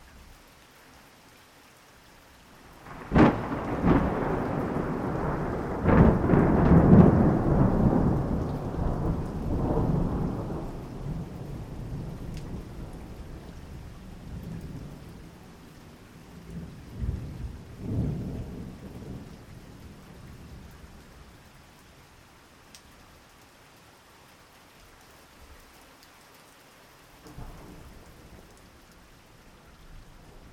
Cette création sans commentaire ni musique accompagnera les auditeurs durant 72 minutes à l'écoute des plus belles découvertes sonores au fil d'une année dans cette magnifique région qu'est le Diois en Drôme.
3 -- Naturophonie estivale